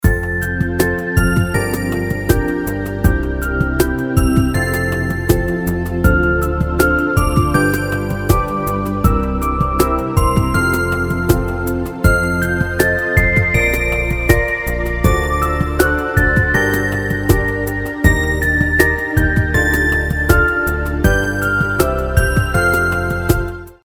• Качество: 320, Stereo
красивые
спокойные
без слов
инструментальные